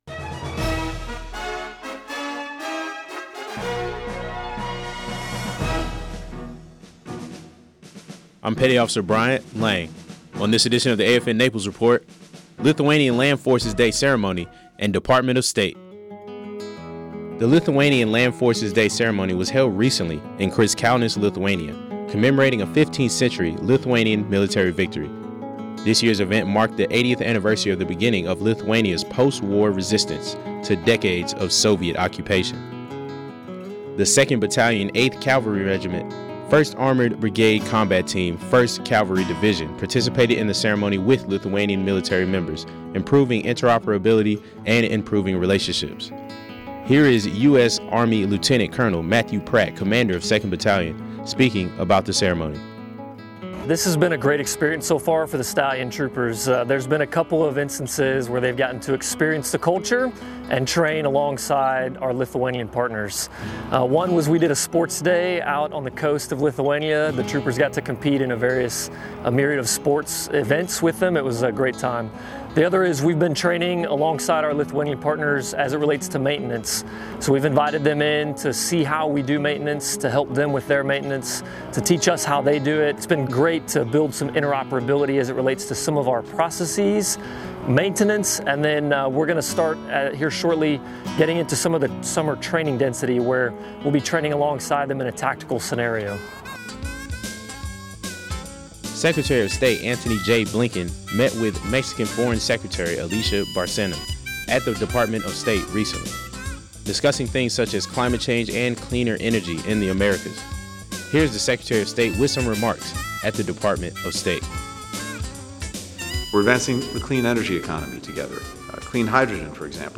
Regional news highlighting Lithuanian Land Forces Day and the Secretary of State speaking at the Department of State.